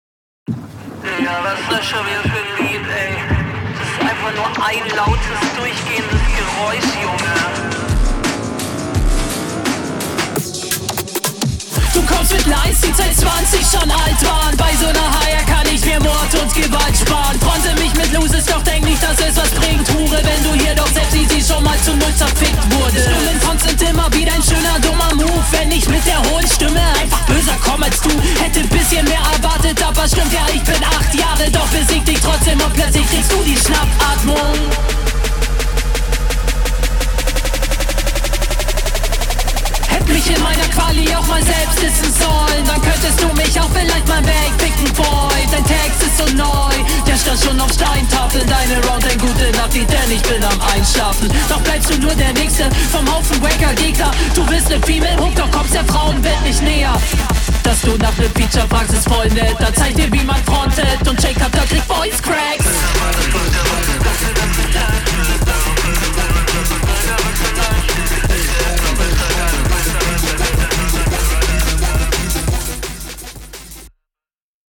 Ok, das kommt echt sick rüber auf dem Beat.